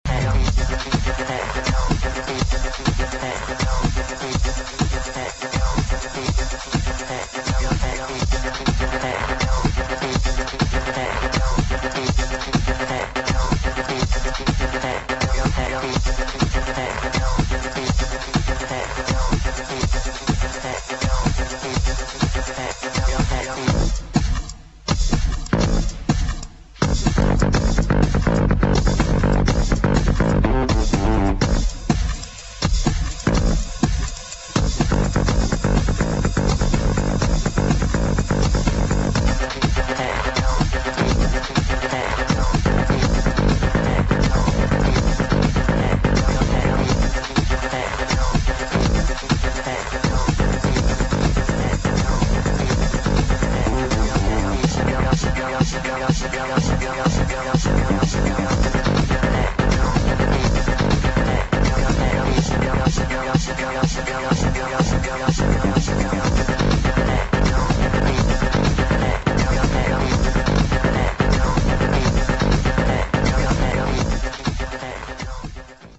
[ BIG BEAT | ELECTRO ]